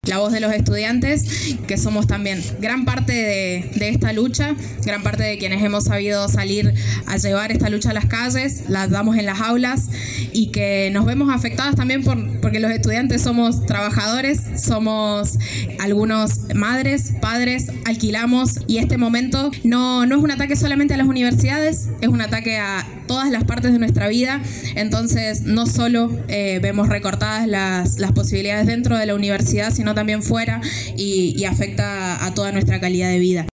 Para aportar adherentes a esta iniciativa, en la concentración de ayer en plaza San Martín se instaló una mesa con planillas donde los manifestantes pudieron firmar su adhesión al proyecto de ley.